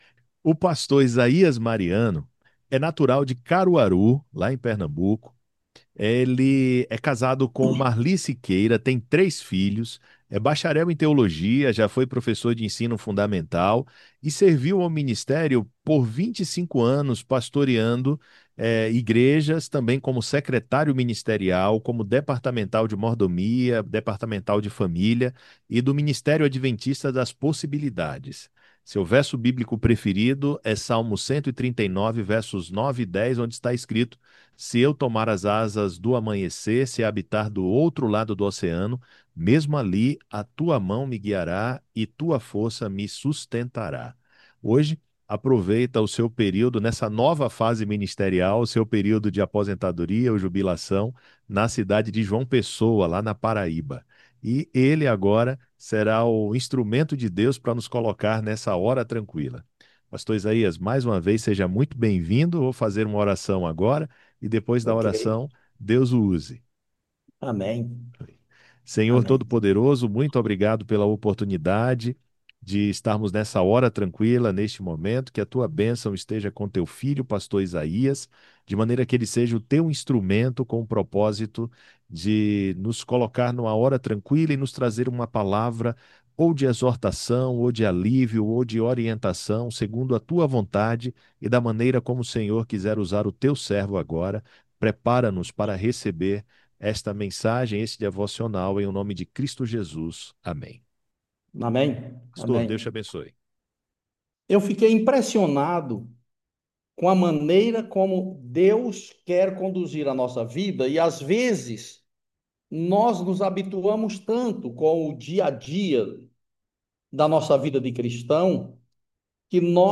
A Hora Tranquila é um devocional semanal.